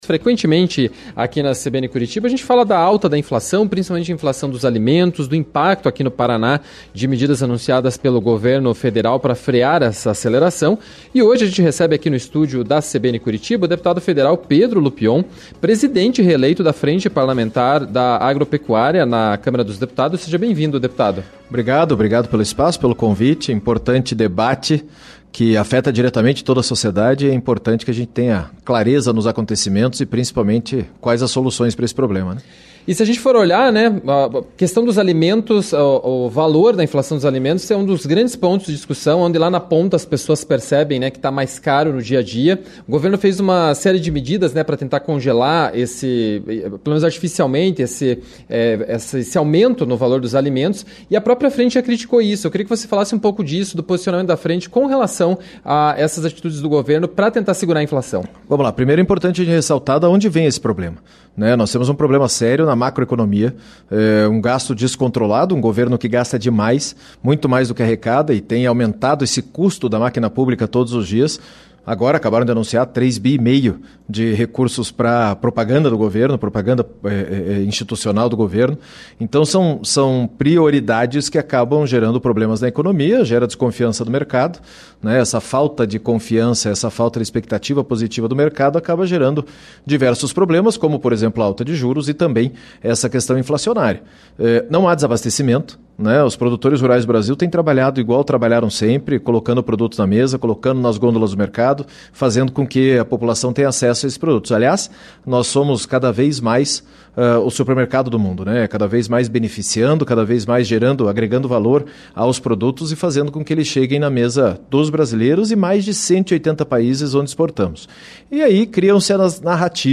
ENTREVISTA-BRUTA-DEPUTADO-PEDRO-LUPION-SEGUNDA-TARDE.mp3